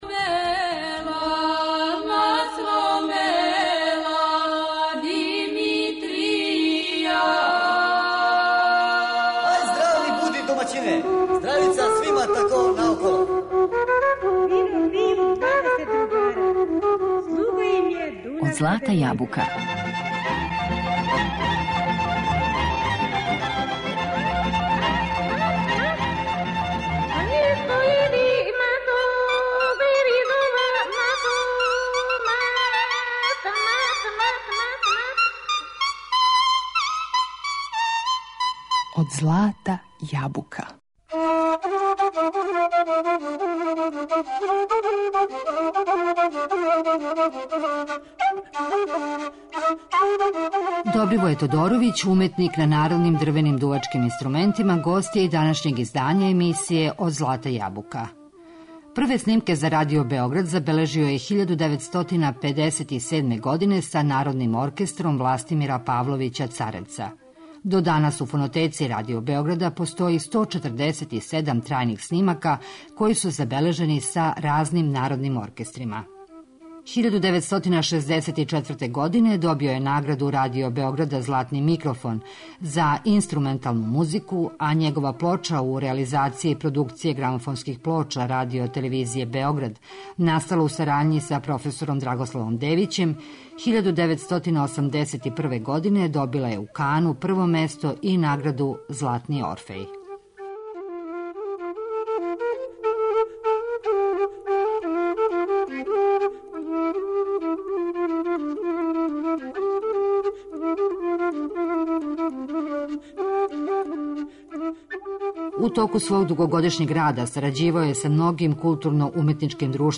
Данас емитујемо други део емисије.